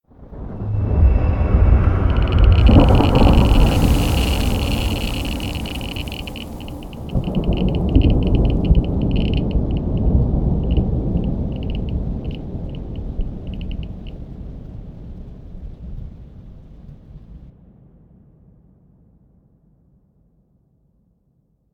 nuclear-explosion-aftershock-2.ogg